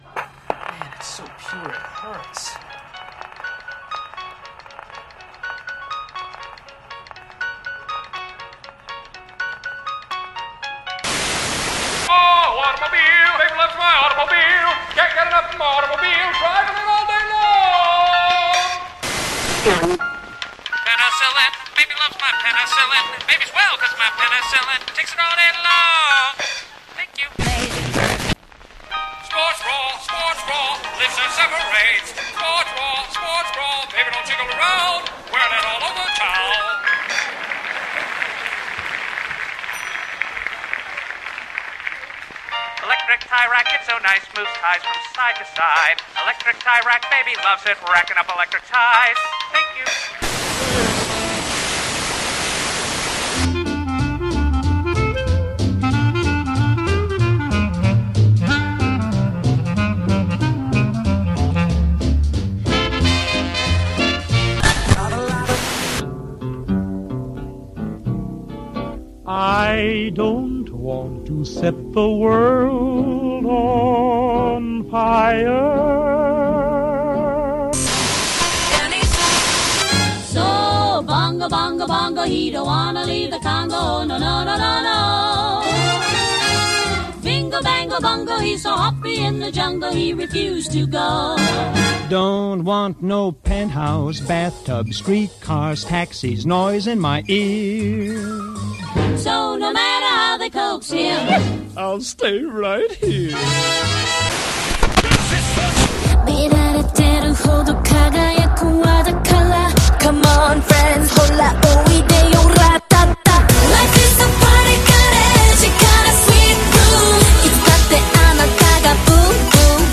miscellaneous instrumental remixes